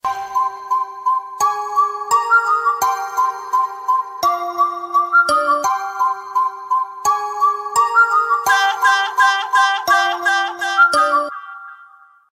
Sound Effects Ringtones